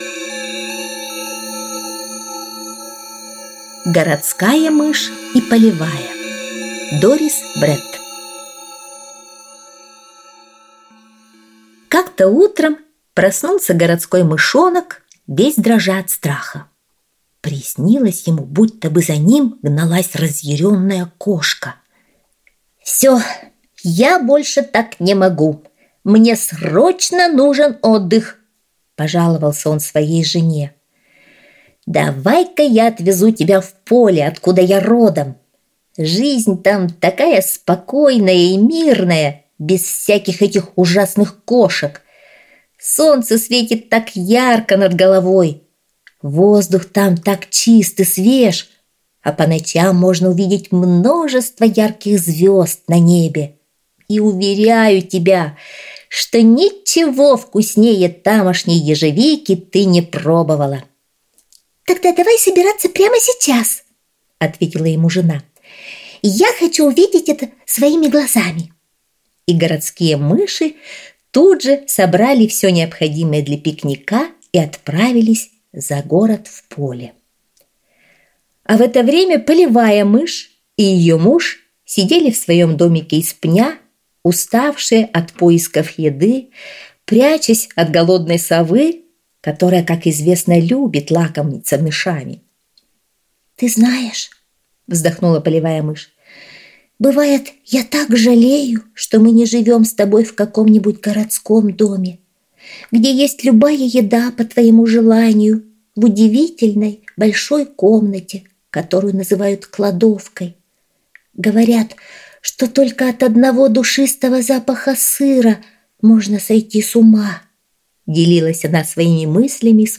Городская мышь и полевая - аудиосказка Бретт - слушать